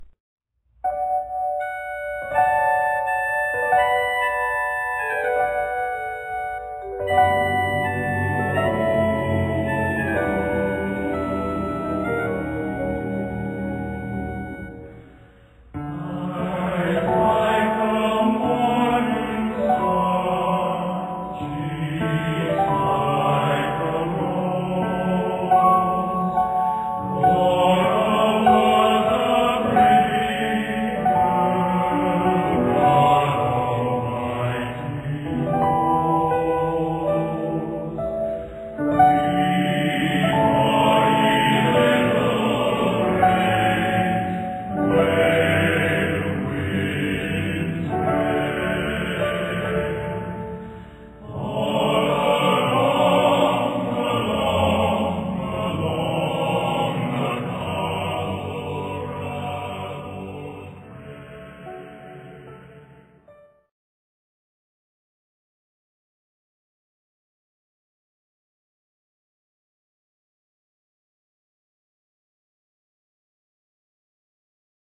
Accompaniment:      Piano
Music Category:      Choral